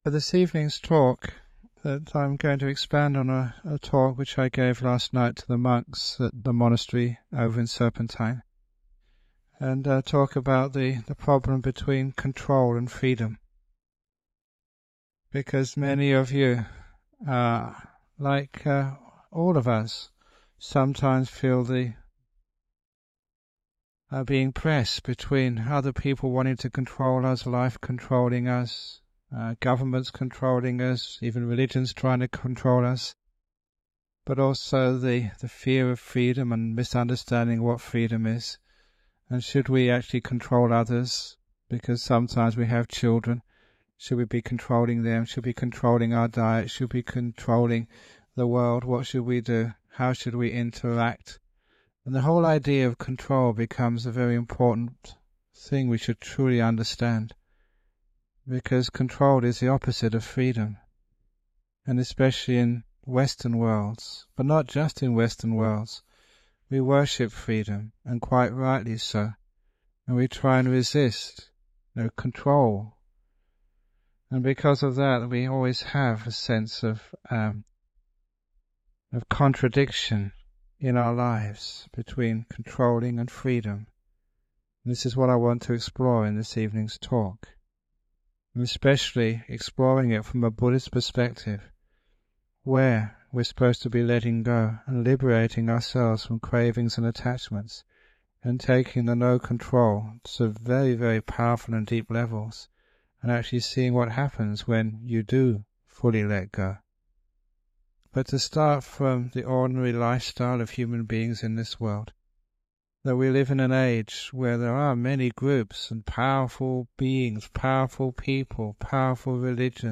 Ajahn Brahm gives a skillful talk about how to relate to the challenges in life. — This dhamma talk was originally recorded using a low quality MP3 to save on file size on 10th November 2006.